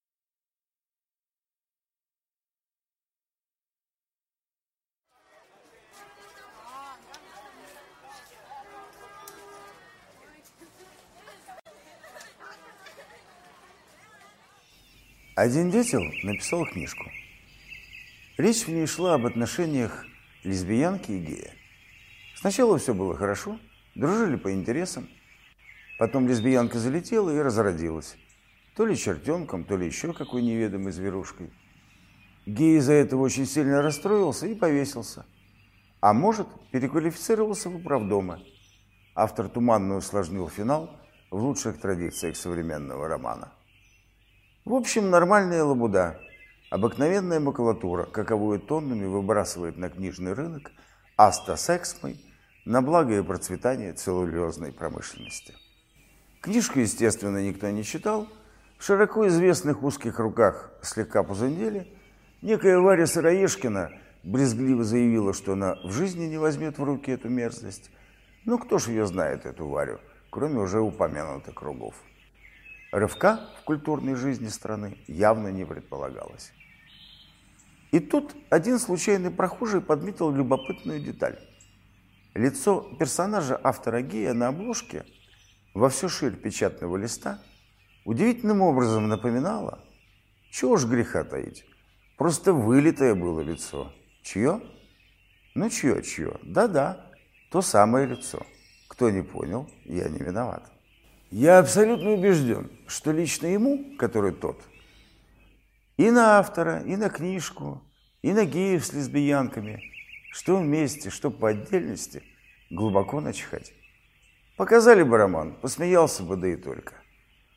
Аудиокнига Бестселлер | Библиотека аудиокниг